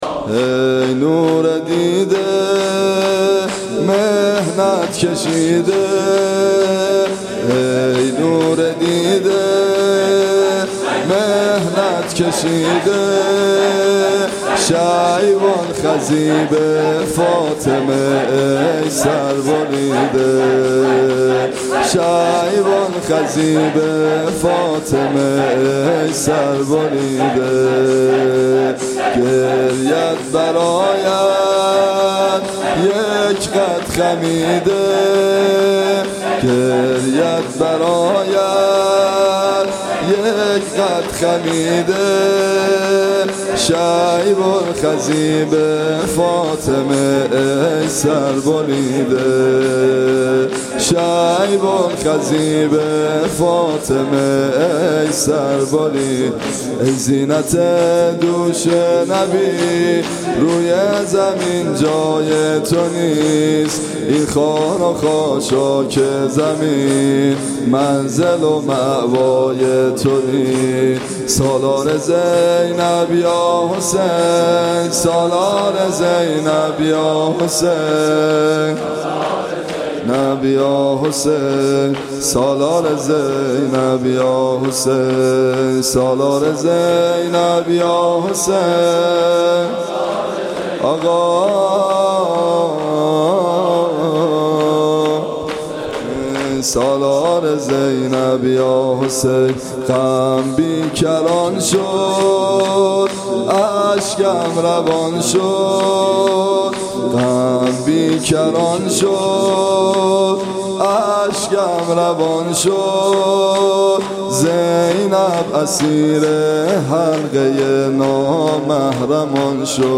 صوت مراسم شب دوم محرم ۱۴۳۷ هیئت ابن الرضا(ع) ذیلاً می‌آید: